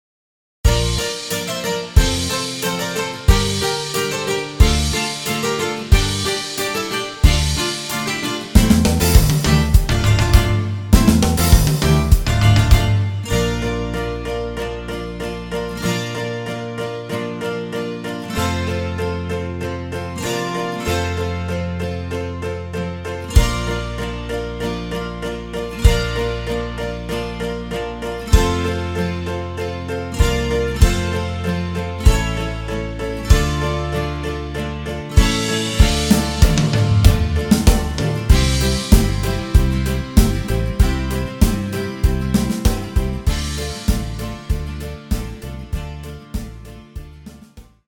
(sans choeurs)